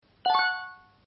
场景1主角获取奖励音效.mp3